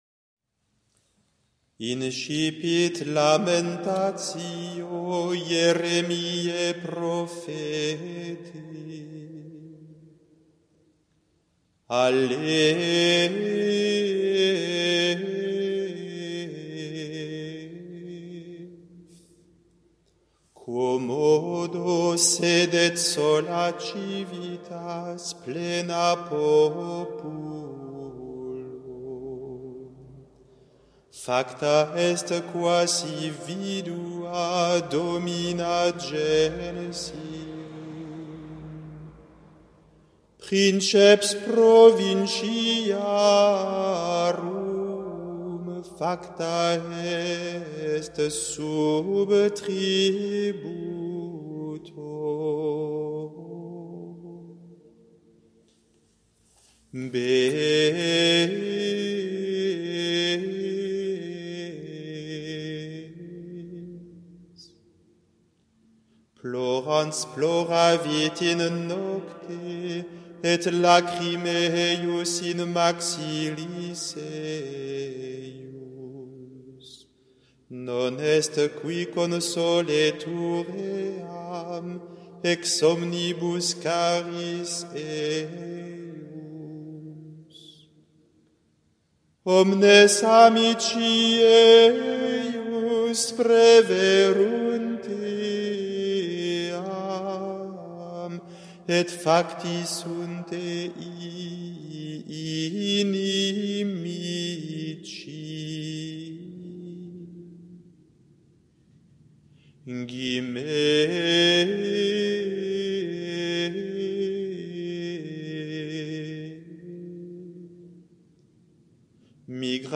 L’Eglise, dans sa tradition liturgique, reprend ce chant des lamentations pendant la Semaine Sainte, alors qu’elle s’unit à la souffrance du Christ et pleure sa mort. Le chant qui est joint à cet article est une mélodie mozarabe (prise dans le Codex de Silos).
Vous reconnaîtrez le début de chaque verset au son de la lettre hébraïque.